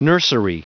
Prononciation du mot nursery en anglais (fichier audio)
Prononciation du mot : nursery
nursery.wav